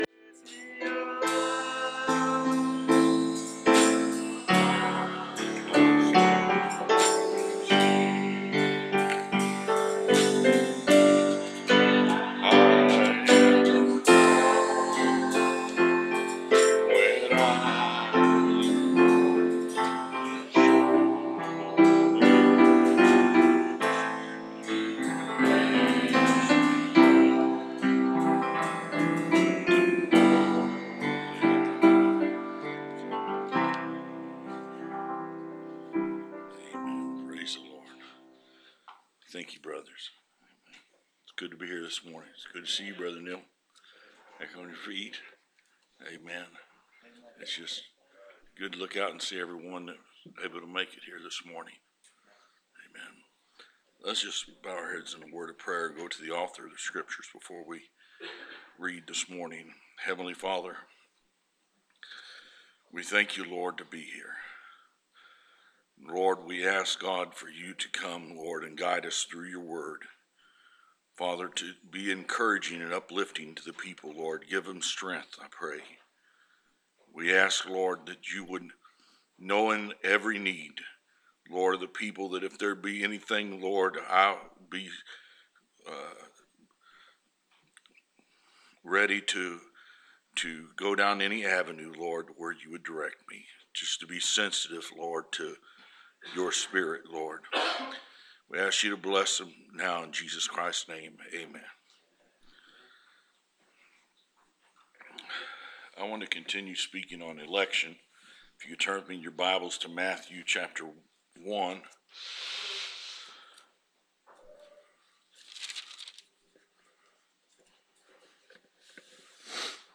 Preached November 6, 2016